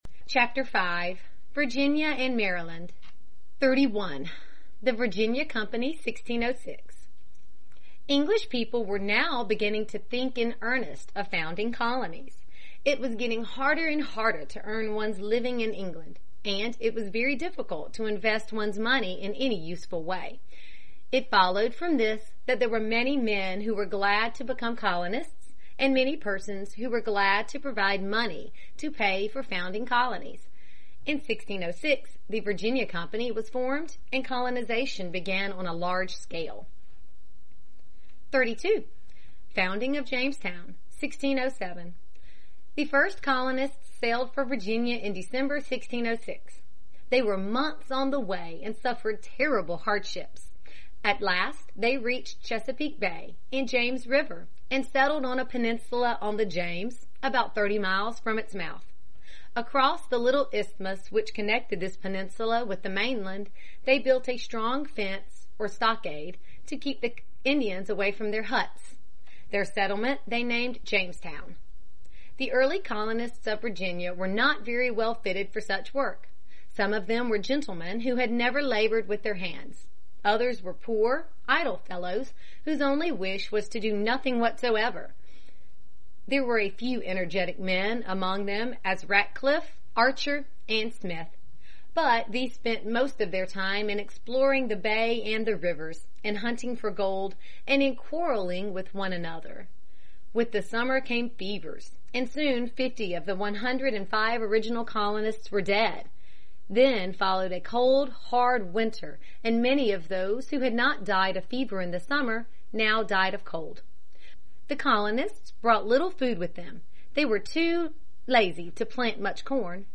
在线英语听力室美国学生历史 第11期:弗吉尼亚和马里兰(1)的听力文件下载,这套书是一本很好的英语读本，采用双语形式，配合英文朗读，对提升英语水平一定更有帮助。